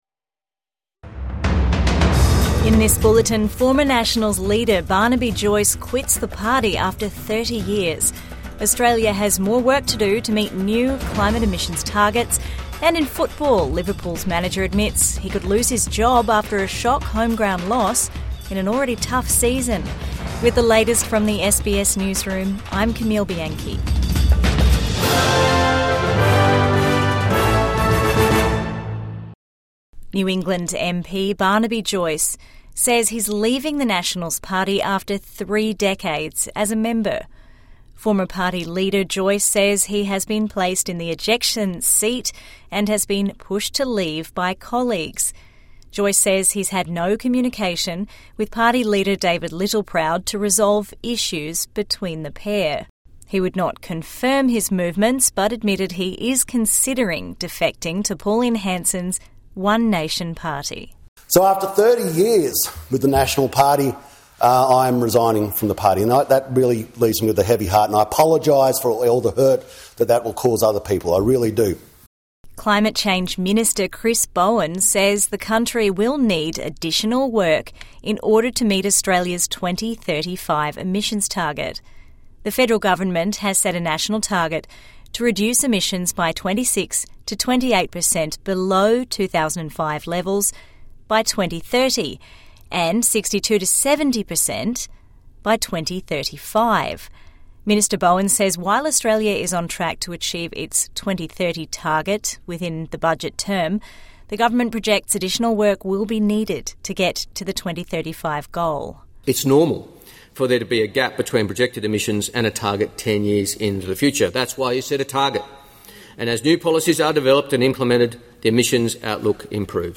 Barnaby Joyce quits Nationals after 30 years | Evening News Bulletin 27 November 2025